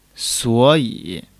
suo3--yi3.mp3